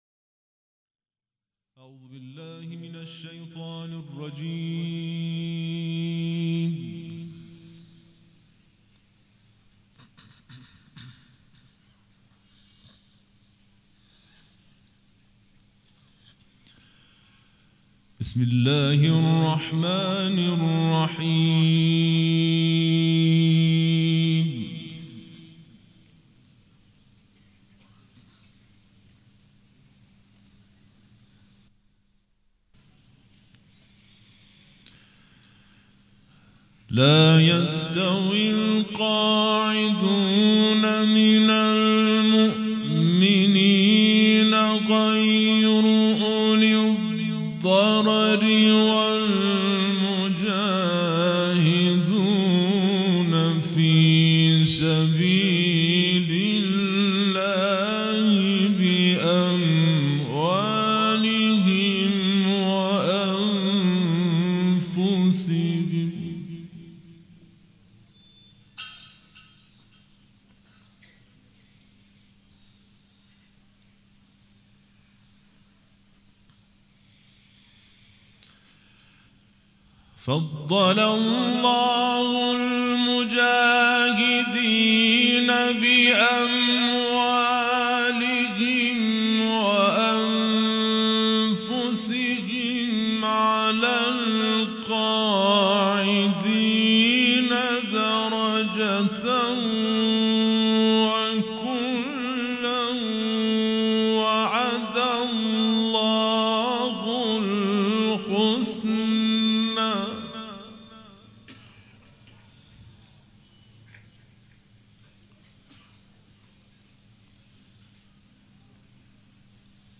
قرائت قرآن کریم
مراسم عزاداری شب سوم